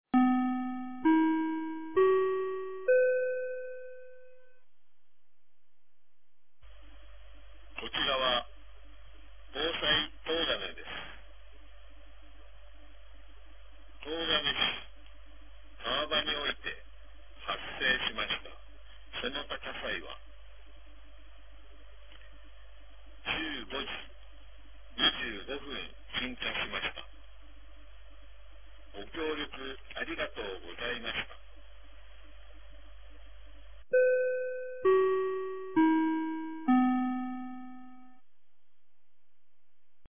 2025年02月01日 15時27分に、東金市より防災行政無線の放送を行いました。